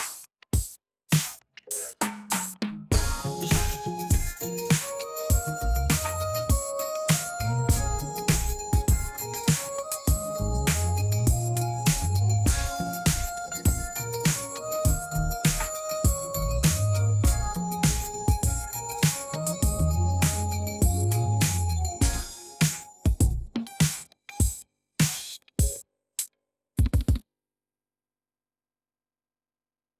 music-generation text-to-audio